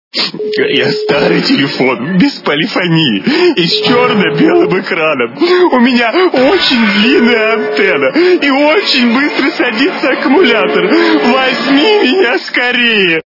При прослушивании Говорящий телефон - Я, старый телефон, с черно-белым экраном. Возьми меня скорее! качество понижено и присутствуют гудки.
Звук Говорящий телефон - Я, старый телефон, с черно-белым экраном. Возьми меня скорее!